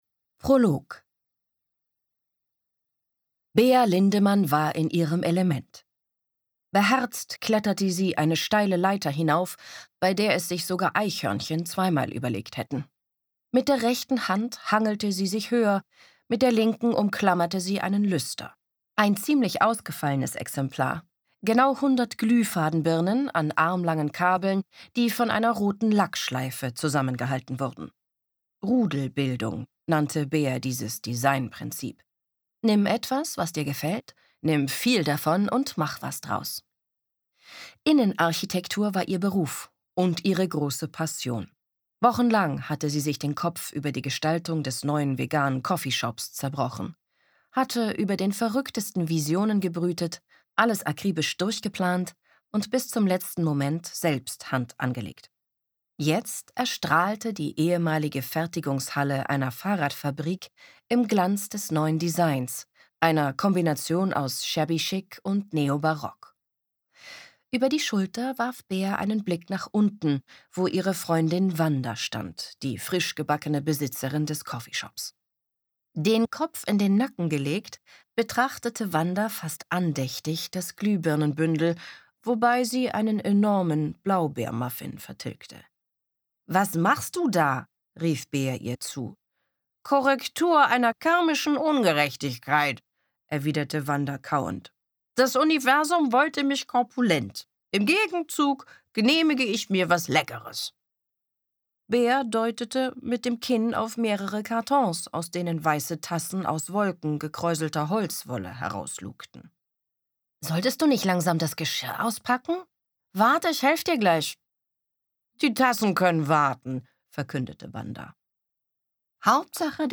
Tessa Mittelstaedt (Sprecher)
Für Aufbau Audio liest sie die Romane von Ellen Berg.